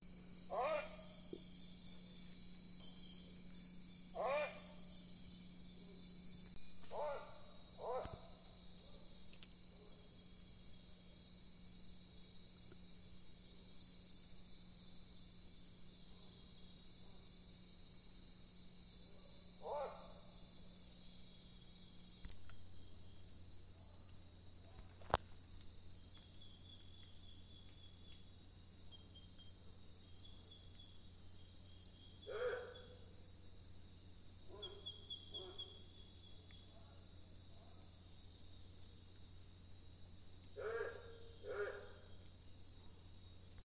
Nictibio Grande (Nyctibius grandis)
Nombre en inglés: Great Potoo
Condición: Silvestre
Certeza: Vocalización Grabada